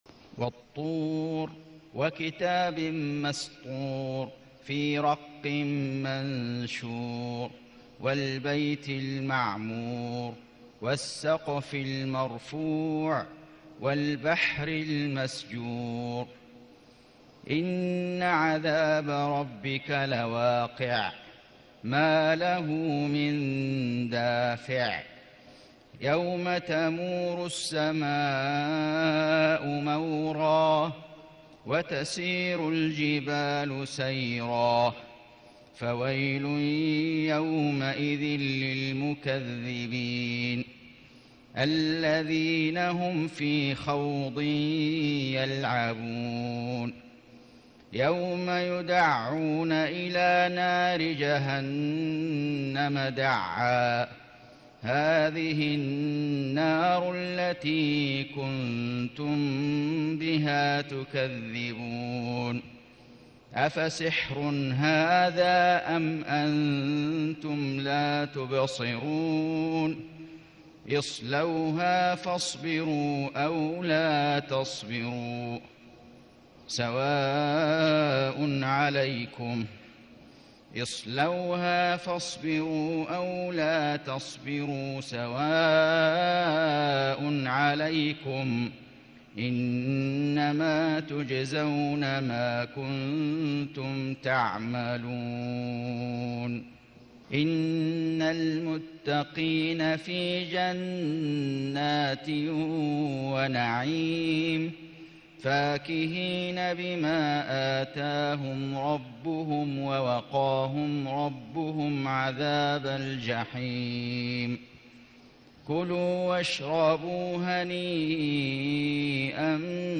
سورة الطور > السور المكتملة للشيخ فيصل غزاوي من الحرم المكي 🕋 > السور المكتملة 🕋 > المزيد - تلاوات الحرمين